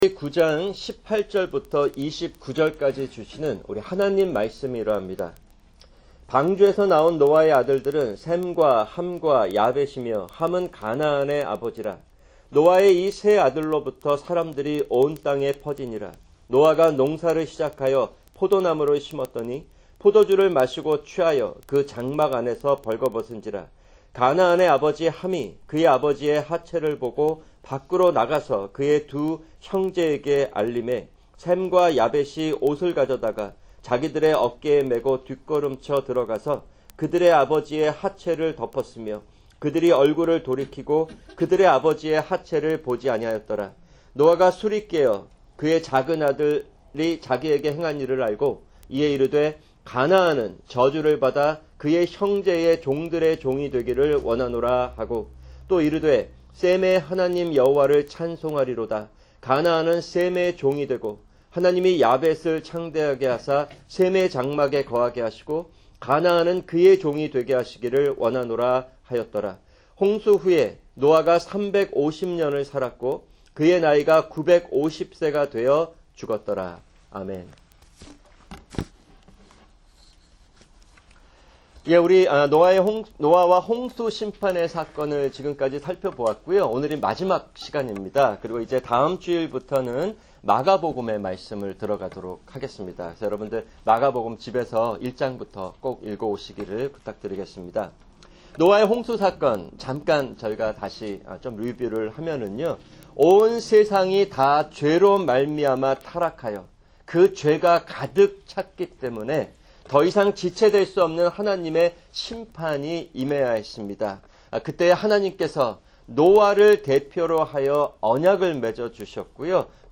[주일 설교] 창세기 9:18-29